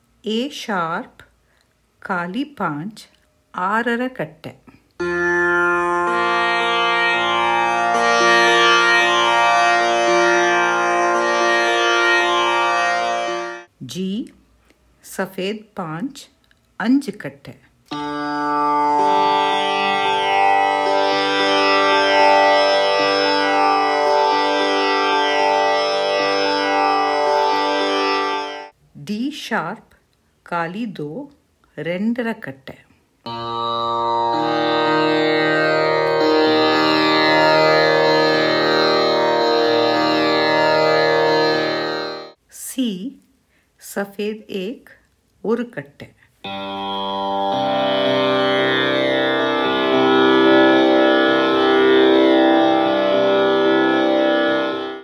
[woocs show_flags=0 width='130px' flag_position='right' txt_type='desc']The Saarang Maestro NEO, the best-selling digital dual tambura (tanpura), has rich natural tanpura sound, using next-gen technology. It has stereo output, and can be used as single or dual tanpura, 4 or 5 string, with automatic tuning of 4 notes and manual tuning of 5th note.